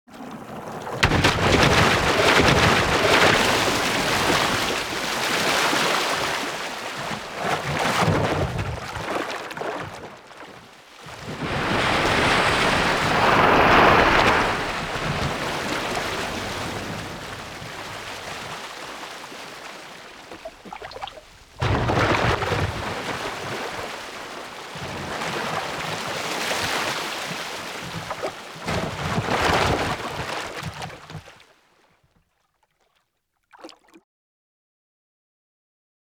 animal
Shark Hit with Double Splash